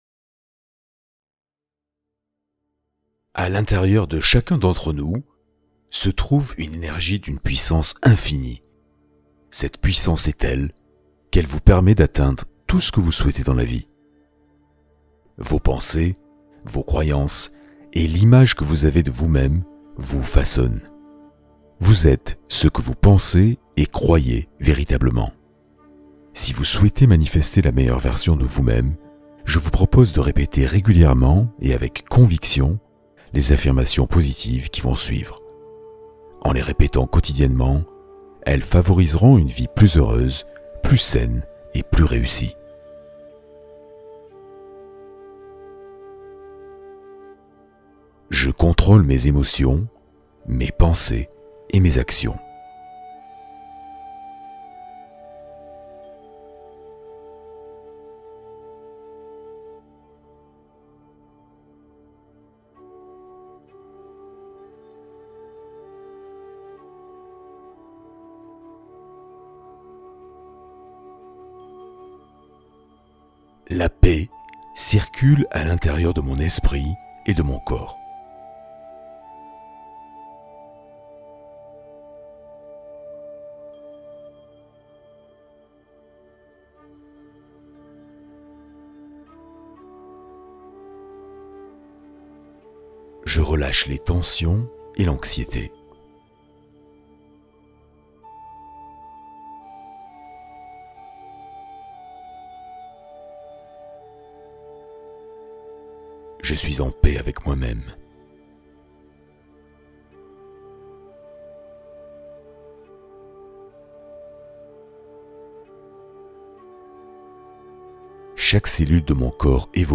Hypnose d’abondance : connexion méditative au soi supérieur